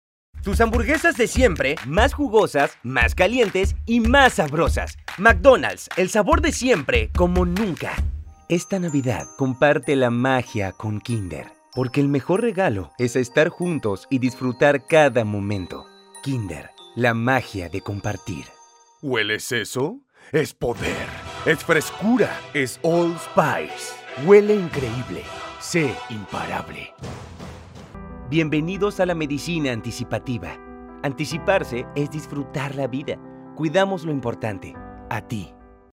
Actor de doblaje · Locutor
Español Neutro
espanol-neutro.mp3